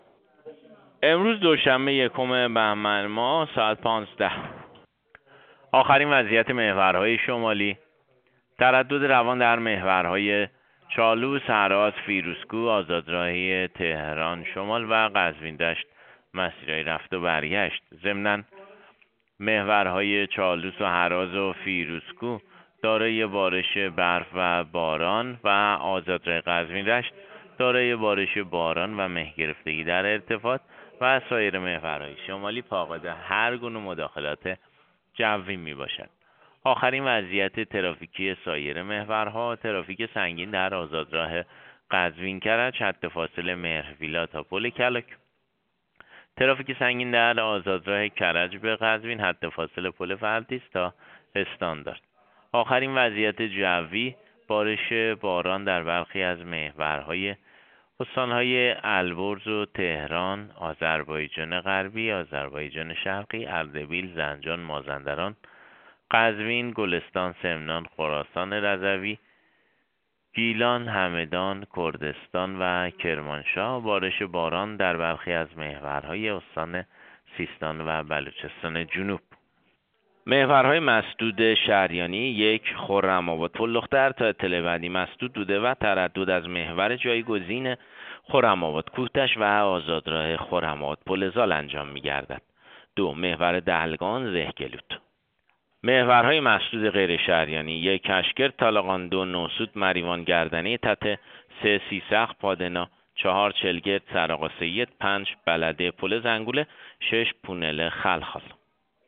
گزارش رادیو اینترنتی از آخرین وضعیت ترافیکی جاده‌ها ساعت ۱۵ روز یکم بهمن؛